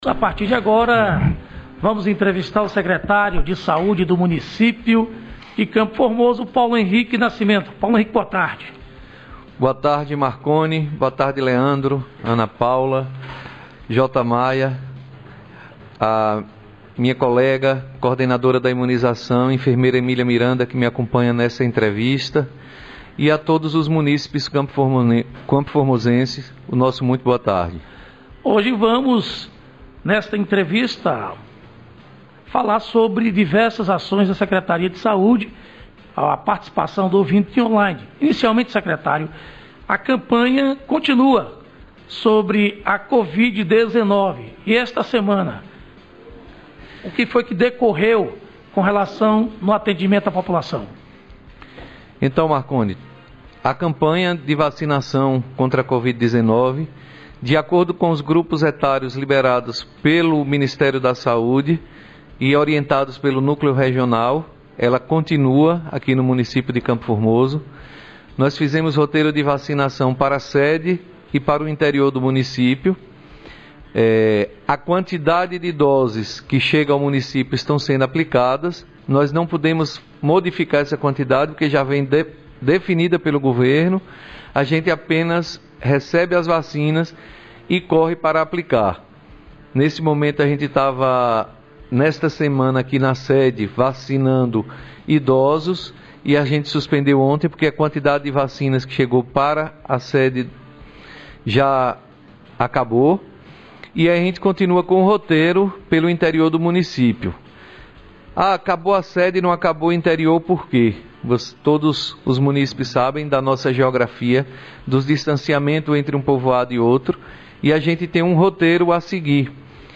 Entrevista- Paulo Henrique secretário de saúde